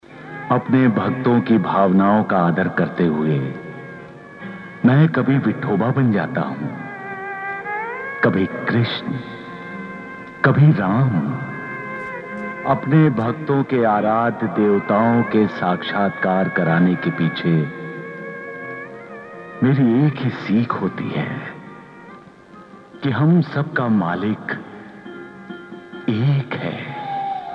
Some dialogues from the Ramanand Sagar's SAIBABA,